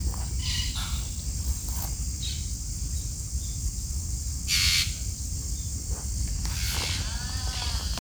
Caturrita (Myiopsitta monachus)
Nome em Inglês: Monk Parakeet
País: Argentina
Localidade ou área protegida: Concordia
Condição: Selvagem
Certeza: Observado, Gravado Vocal